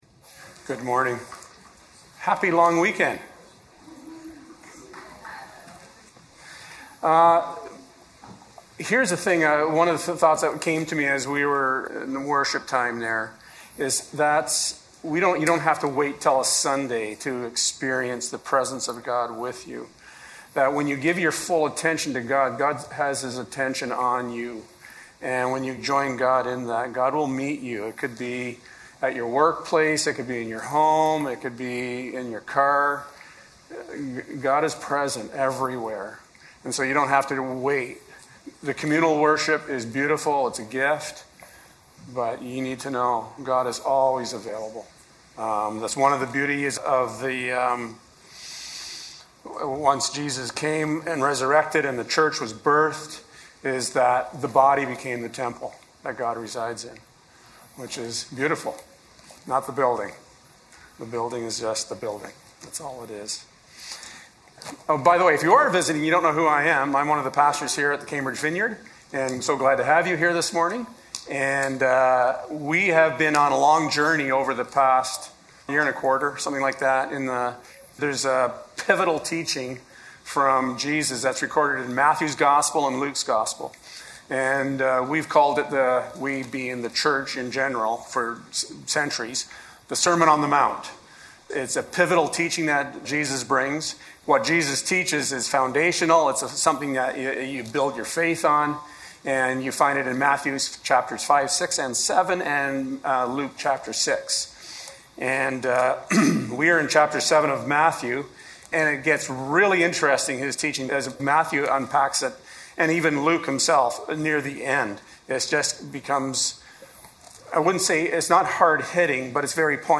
15-20 Service Type: Sunday Morning God is kind.